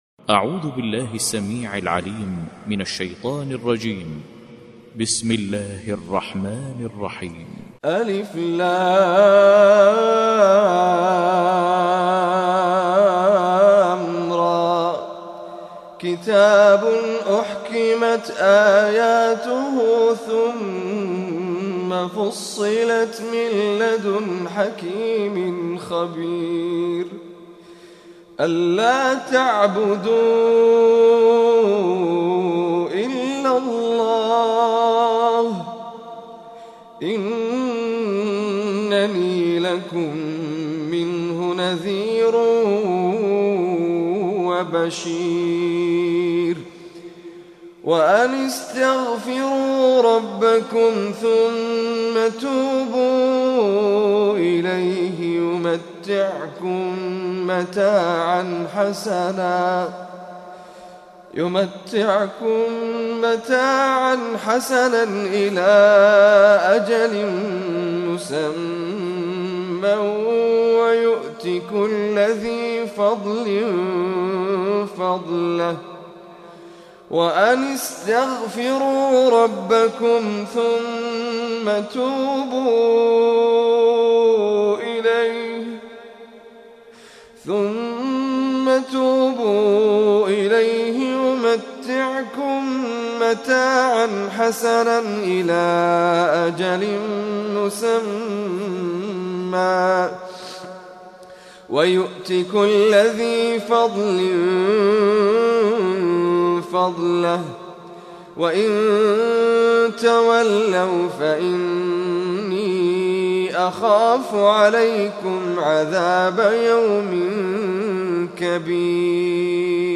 Surah Hud, listen or play online mp3 tilawat / recitation in Arabic in the beautiful voice of Sheikh Abdul Aziz Az Zahrani.